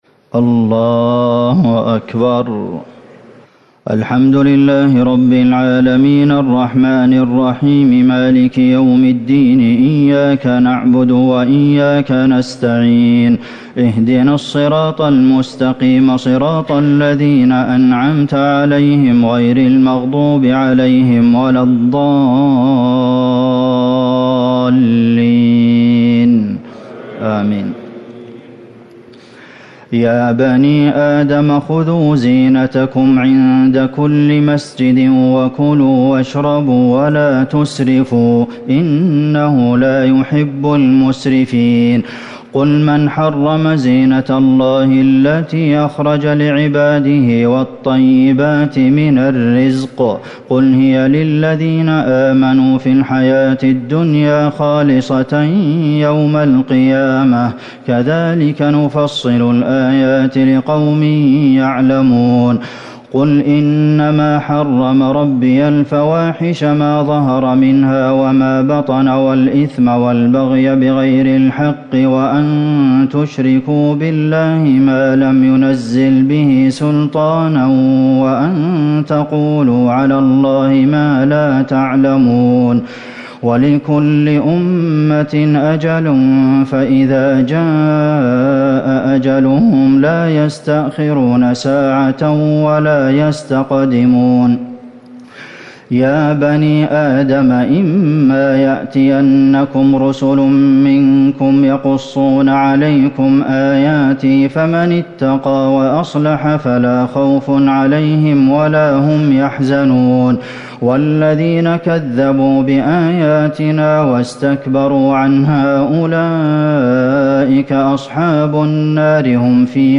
تهجد ٢٨ رمضان ١٤٤٠ من سورة الأعراف ٣١ - ٨٤ > تراويح الحرم النبوي عام 1440 🕌 > التراويح - تلاوات الحرمين